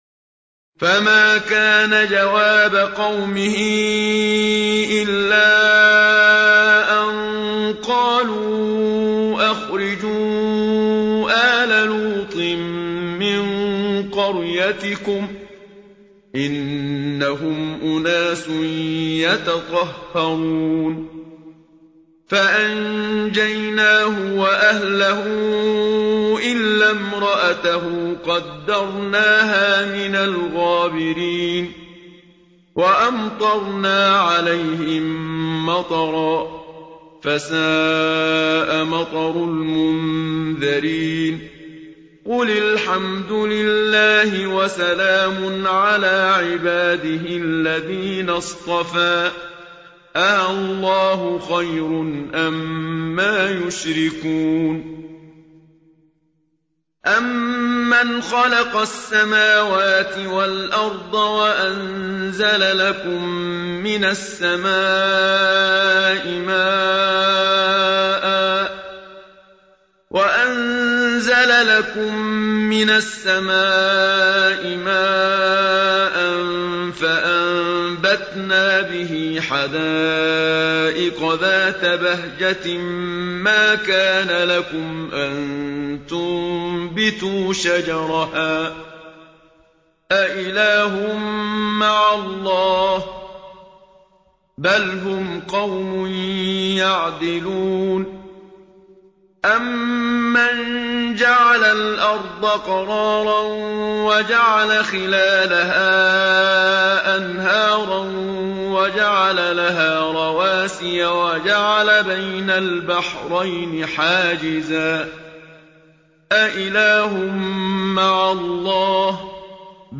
الختمة المرتلة بصوت الشيخ المنشاوي الجزء (20)
إذاعة طهران- الختمة المرتلة: تلاوة الجزء العشرين من القرآن الكريم بصوت القارئ الشيخ محمد صديق المنشاوي.